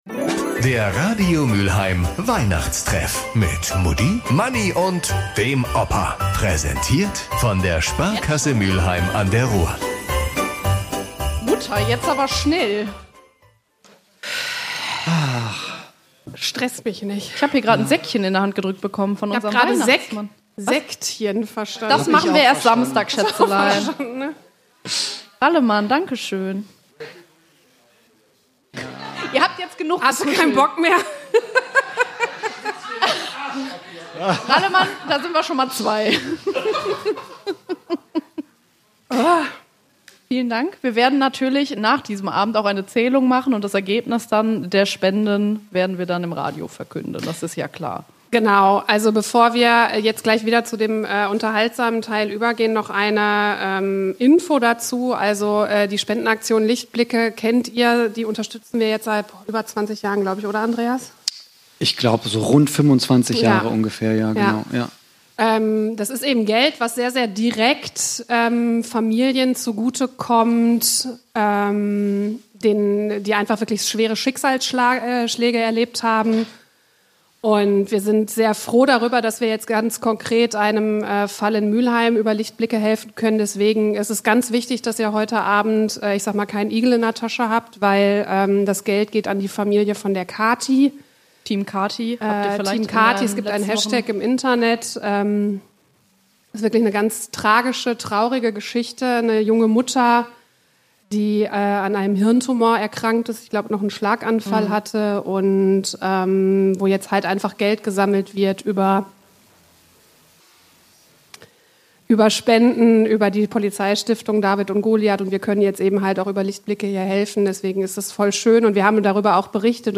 Beschreibung vor 1 Jahr Gefühlt hat der Heiligabend schon stattgefunden: Am 12. Dezember, im Rittersaal vom Schloss Broich.